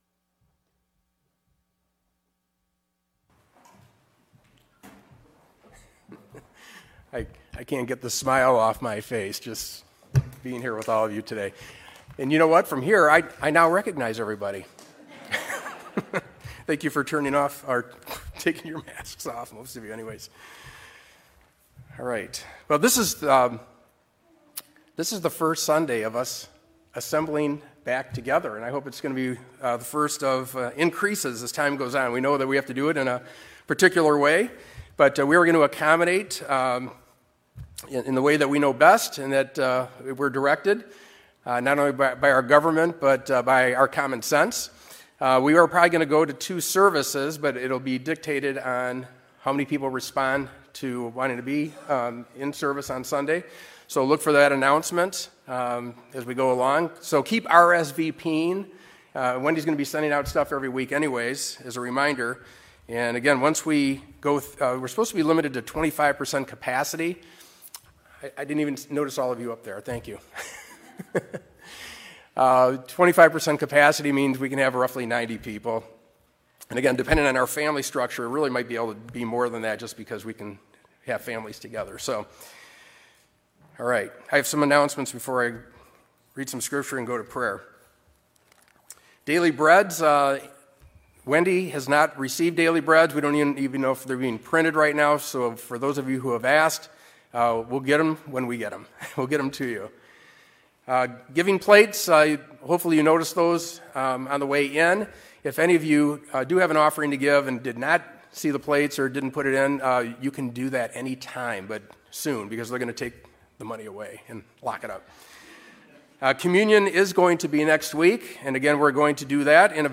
Worship Service 5/31/20